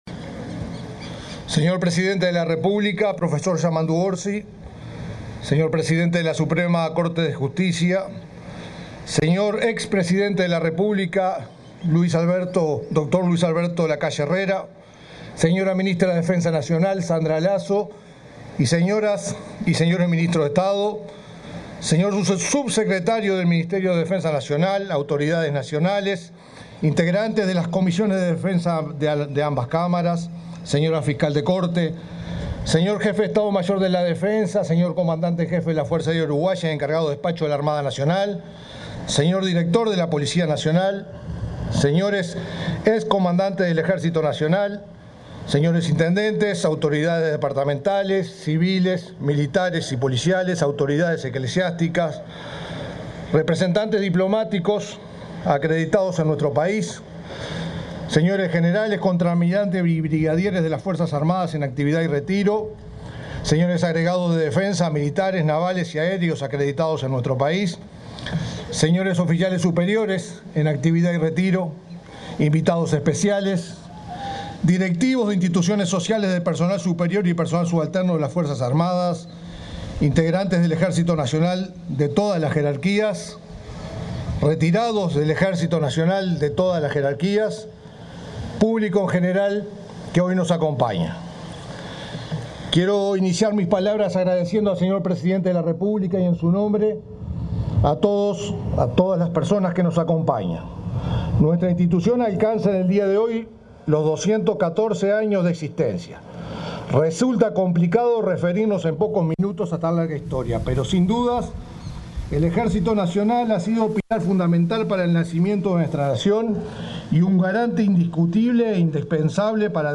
El comandante en jefe del Ejército, Mario Stevenazzi, se expresó durante la conmemoración del Día del Ejército Nacional.